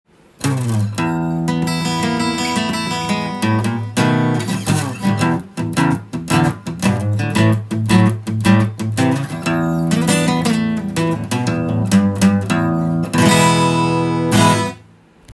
ゴージャズな雰囲気を醸し出すアコースティックギターです。
試しに弾いてみました
音の方は、古風な昔の音がしますのでドンシャリというより
ソフトで爽やかな感じです！